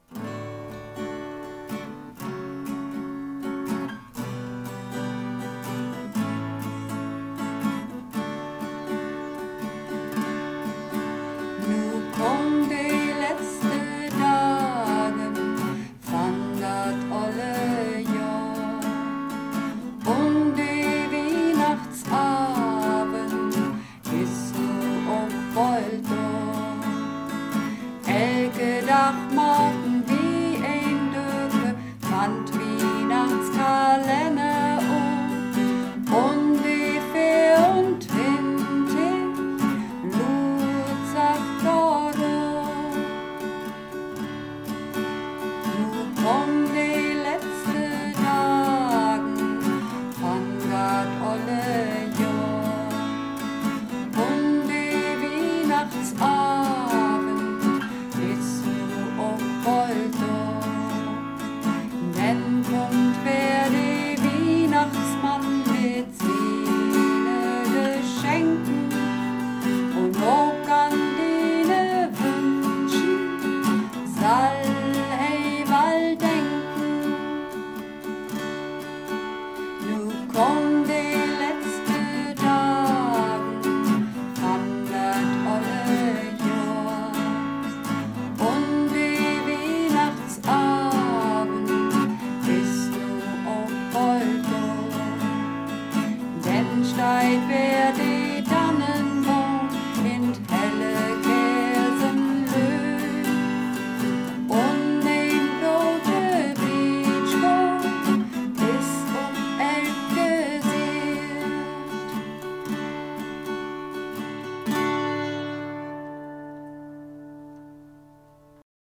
Übungsaufnahmen - De lesde Dagen
De lesde Dagen (Alt und Bass)
De_lesde_Dagen__1_Alt_und_Bass.mp3